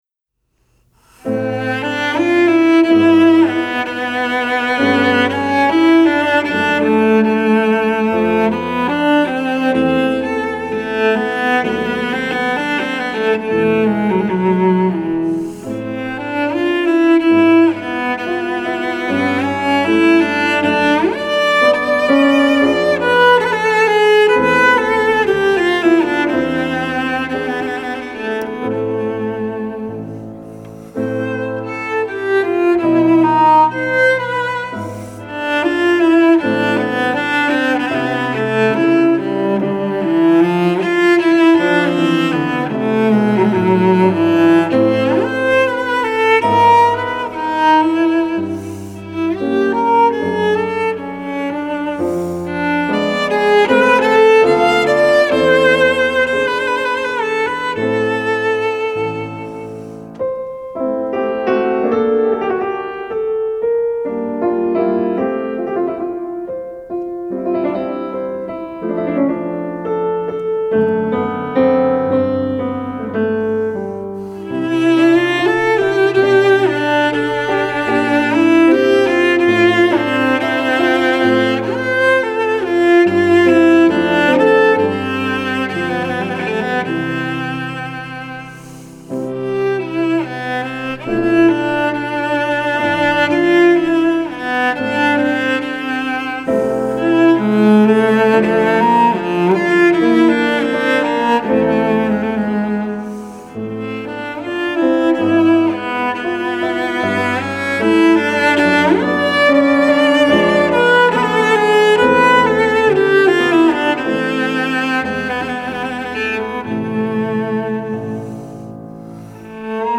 Composer: Performed by Cellist